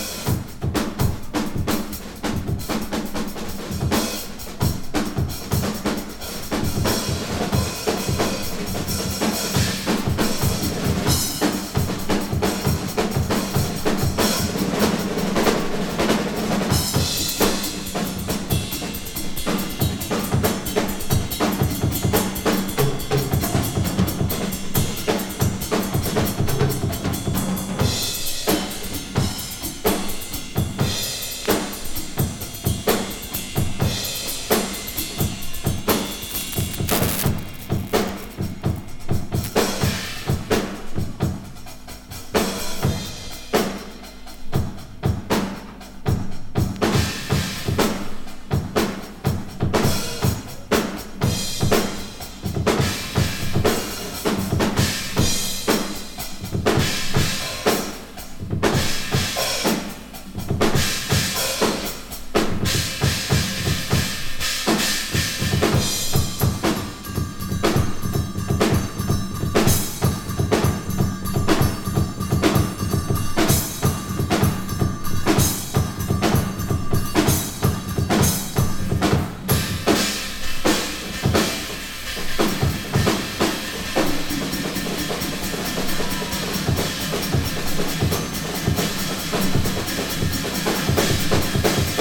イタリアのプログレッシブ・ロック・バンド